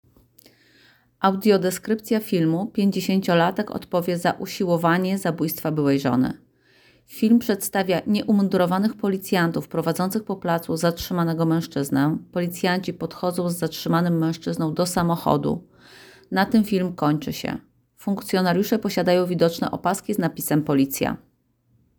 Nagranie audio Audiodeskrypcja_filmu_50-latek_odpowie_za_usilowanie_zabojstwa_bylej_zony.m4a